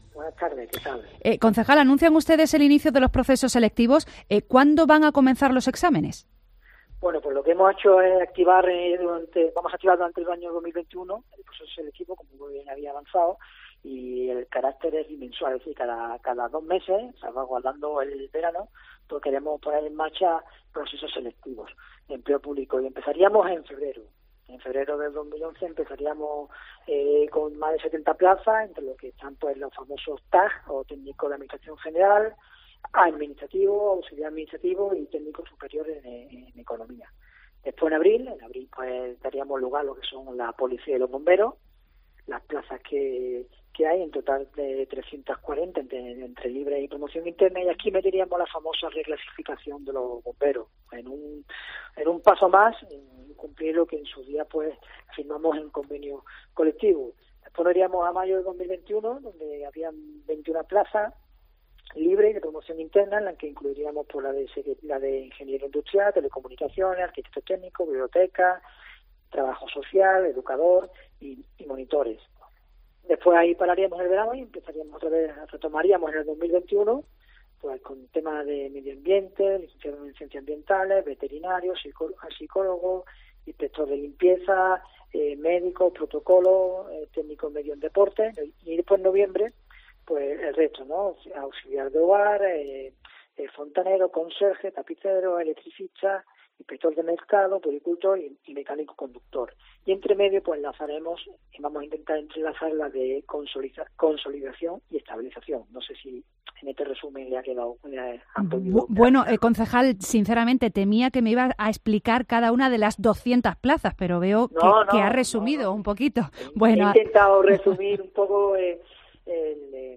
Entrevista con Jacobo Florido en COPE Más Málaga